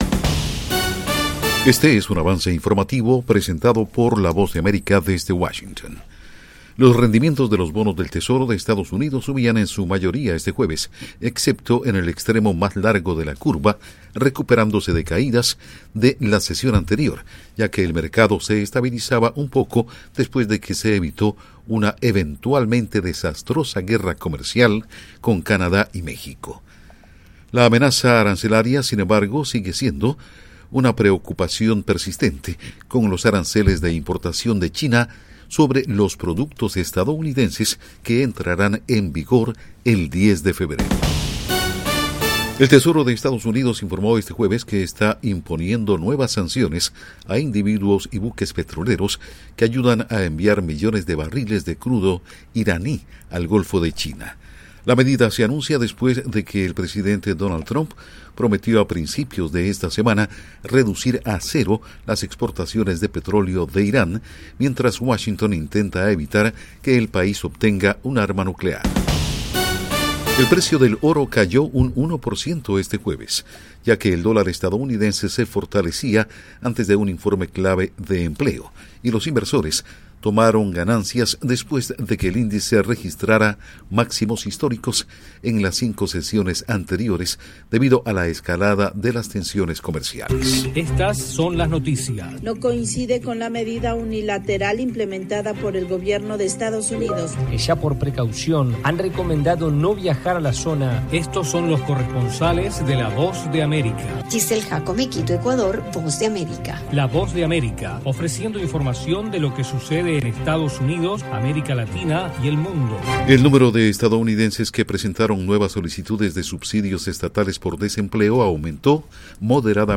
Avance Informativo 2:00 PM | 02/06/2025